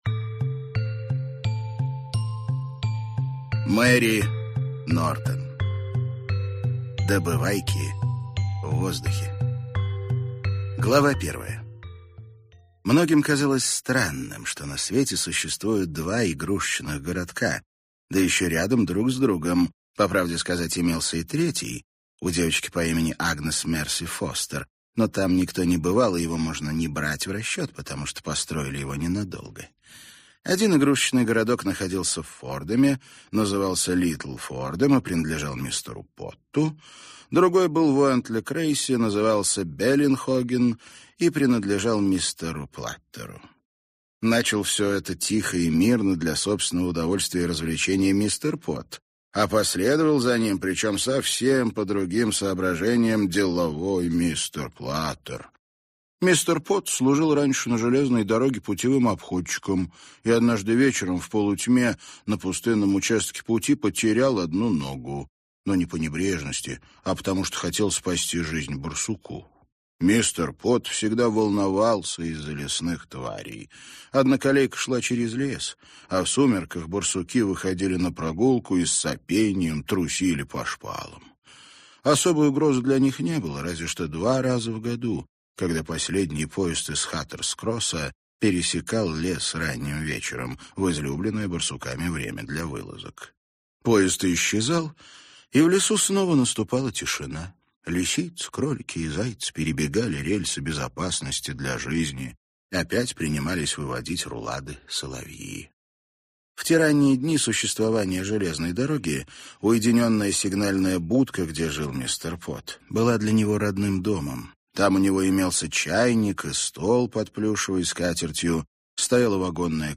Аудиокнига Добывайки в воздухе | Библиотека аудиокниг